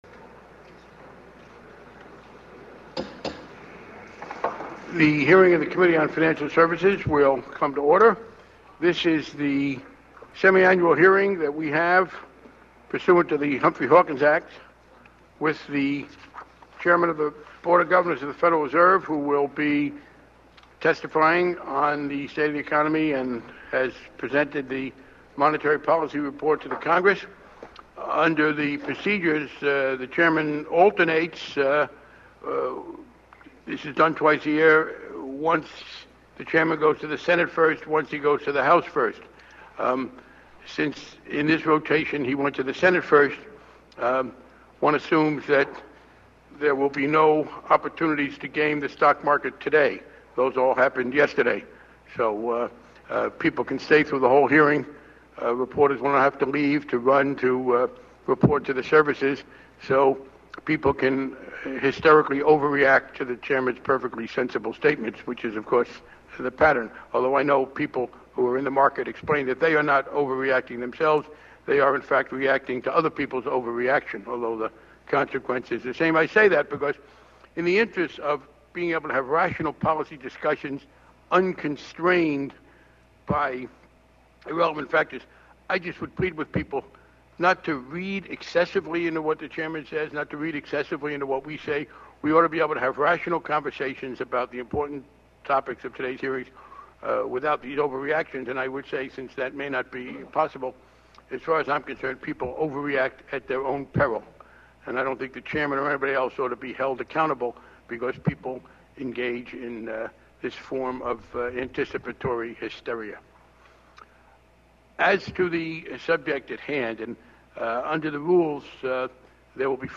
On February 15, 2007, there was a hearing on the state of the economy by the House Committee on Financial Services.
Chairman:
Barney_Frank_Statement.mp3